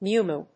/múːmuː(米国英語)/